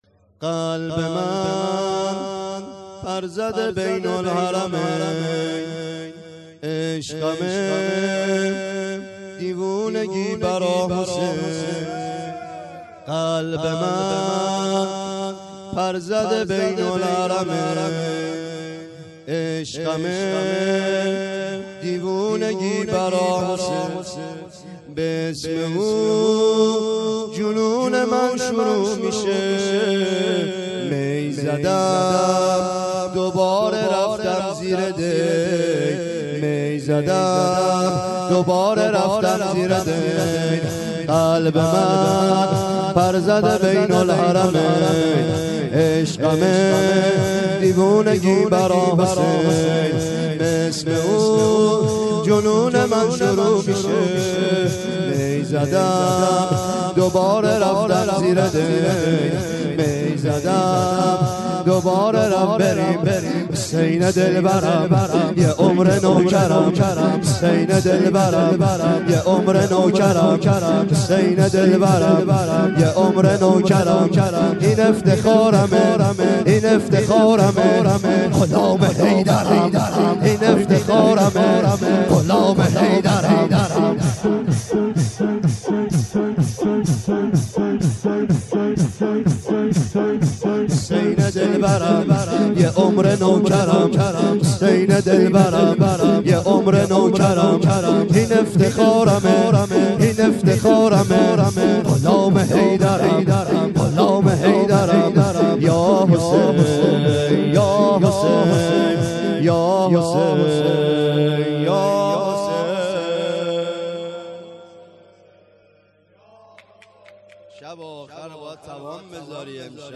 شور : قلبه من پر زده بین الحرمین